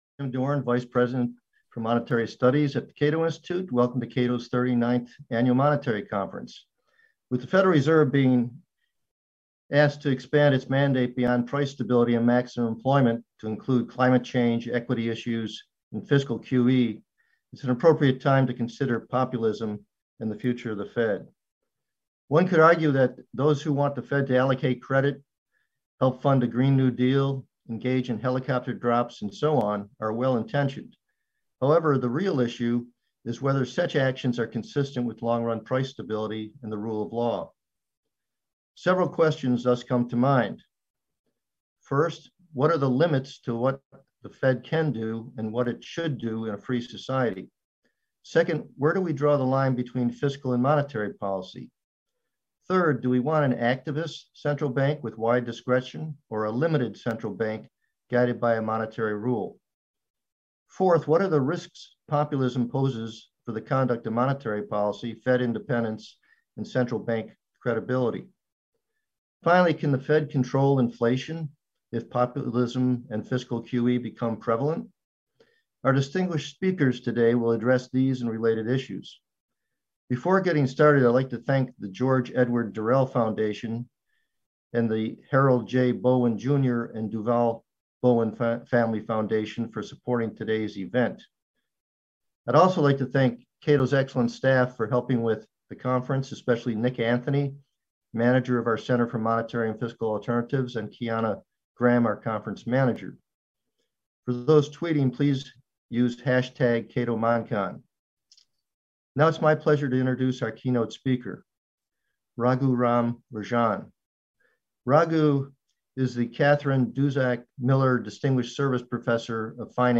39th Annual Monetary Conference: Welcoming and Keynote Address